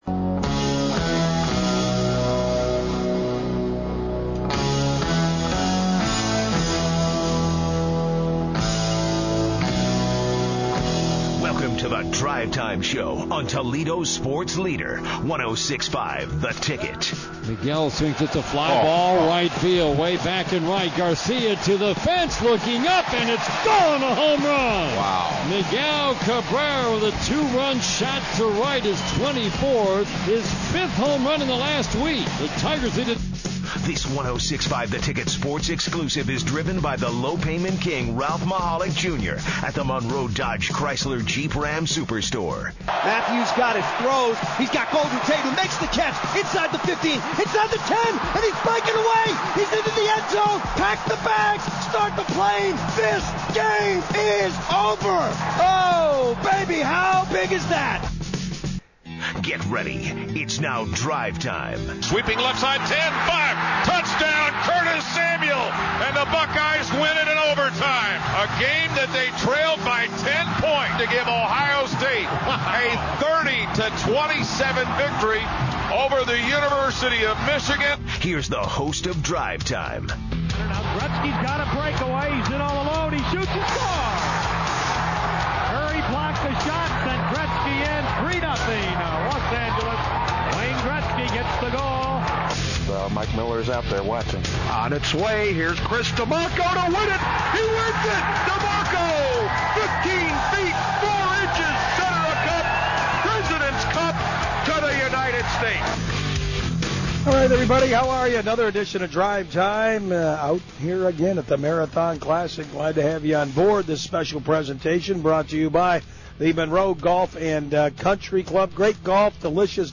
broadcasting live at Highland Meadows for Marathon Classic